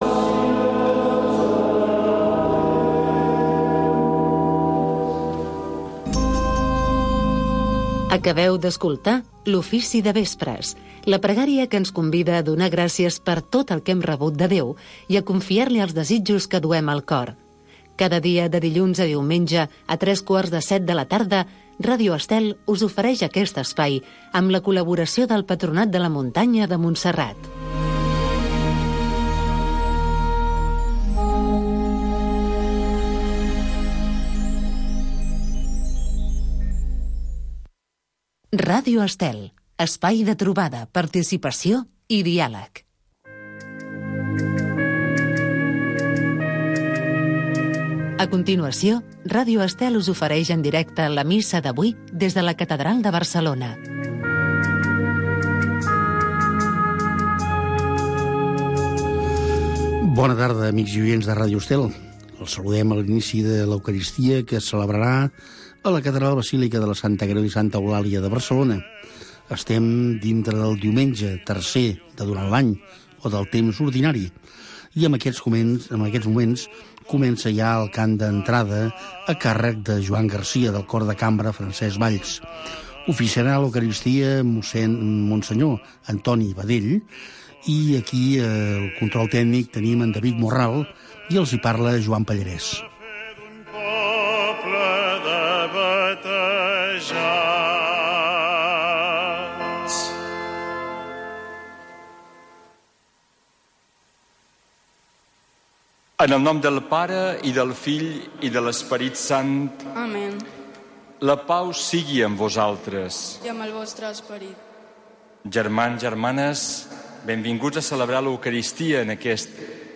La Missa de cada dia. Cada dia pots seguir la Missa en directe amb Ràdio Estel.